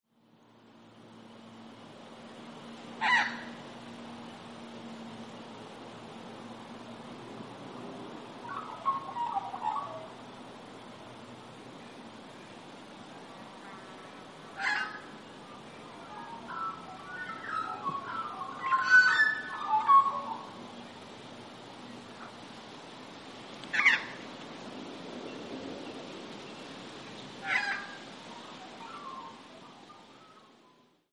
Australian Magpie - Gymnorhina tibicen
Voice: mellow, musical carolling, sometimes at night; short, harsh alarm call; less harsh squawk.
Call 3: group calling and warbling
Aus_Magpie_3_group.mp3